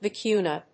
音節vi・cu・ña 発音記号・読み方
/vɪkúːnjə(米国英語), vɑɪúːnjə(英国英語)/